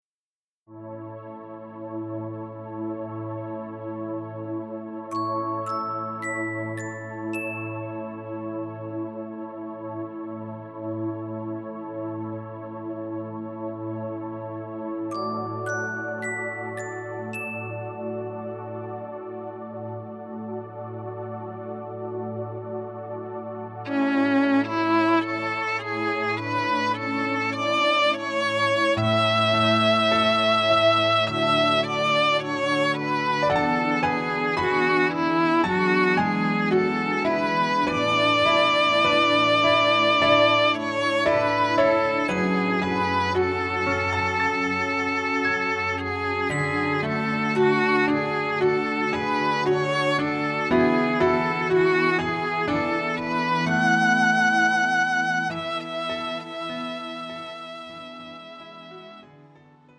음정 여자키 3:06
장르 가요 구분 Pro MR
Pro MR은 공연, 축가, 전문 커버 등에 적합한 고음질 반주입니다.